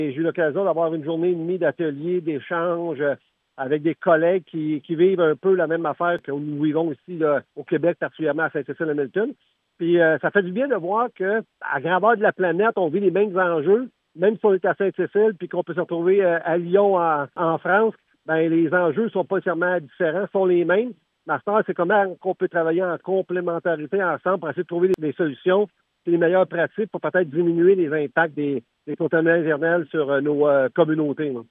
Paul Sarasin, préfet de la MRC Haute-Yamaska.